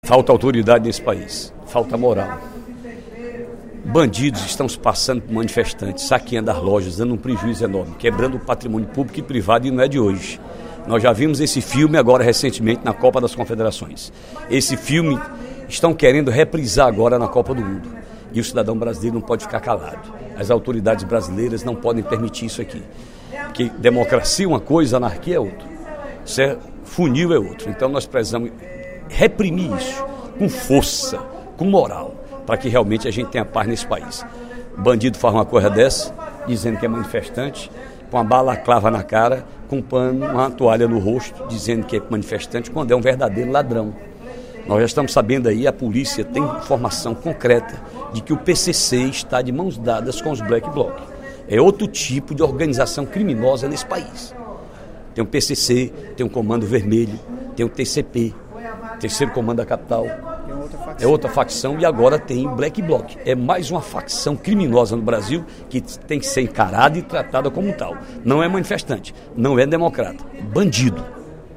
O deputado Ferreira Aragão (PDT) cobrou, no primeiro expediente da sessão desta quinta-feira (05/06), uma abordagem mais intensa da polícia durante os manifestos em Fortaleza, após os danos causados na avenida Dom Luís, na quarta-feira (04/06).
Em aparte, o deputado João Jaime (DEM) reclamou da falta de rigor dos governantes com esse tipo de situação.